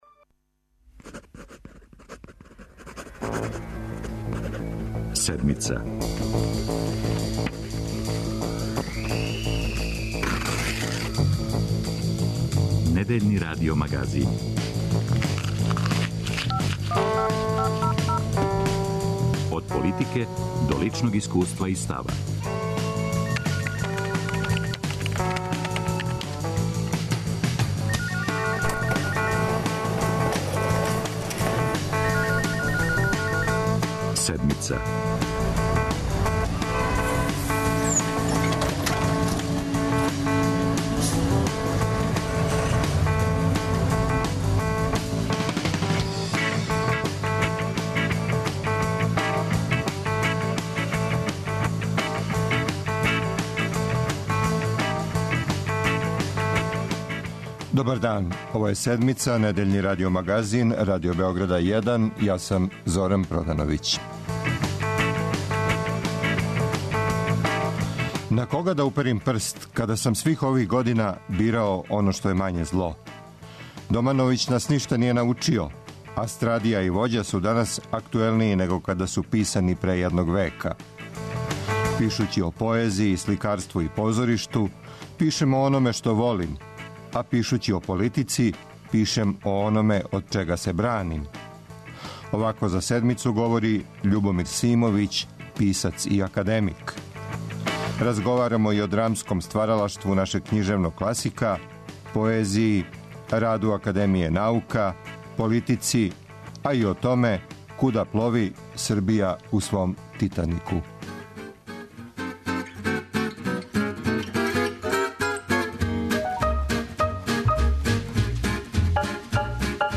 'Пишући и о поезији, сликарству и позоришту, пишем о ономе што волим, а пишући о политици, пишем о ономе од чега се браним.' За Седмицу говори Љубомир Симовић, писац и академик.